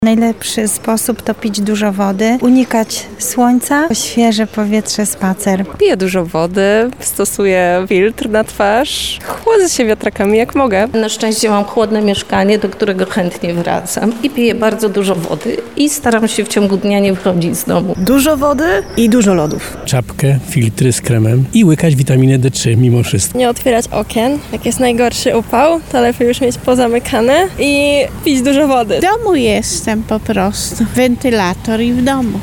Zapytaliśmy mieszkańców Lublina, jak sobie radzą z upałami.
SONDA